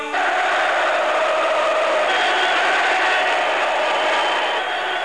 Download the 'Haaaaarold' chant [108K] Written content (except posted articles not written by this site) and design � 2000-Present Canucks Almanac.
snepsts_haroldchant.wav